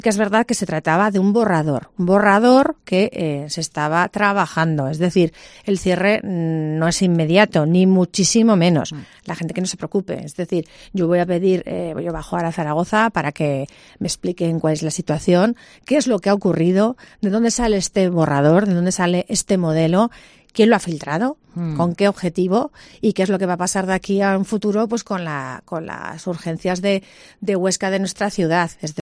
Lorena Orduna, alcaldesa de Huesca